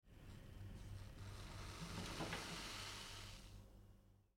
Звуки молока
Звук закипания молока (пенка поднялась) (00:04)